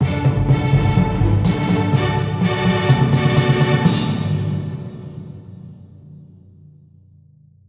Fanfares